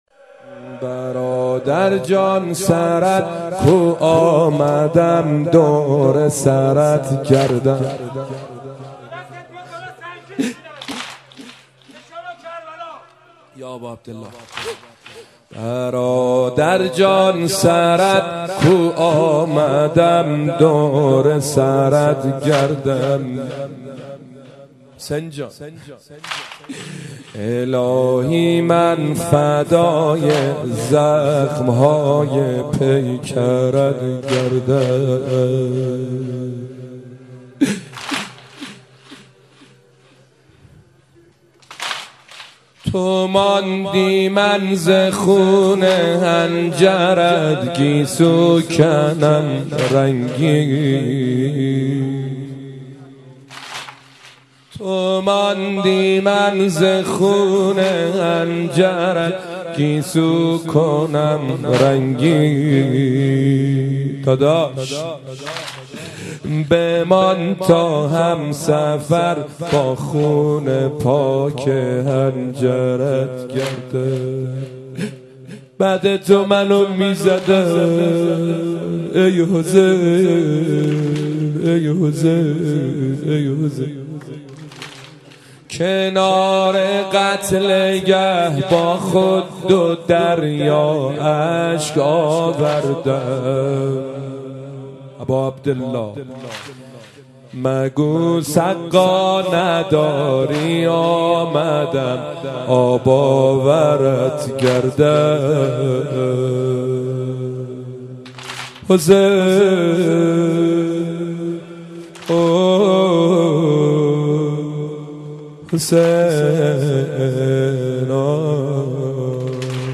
زمینه، روضه، مناجات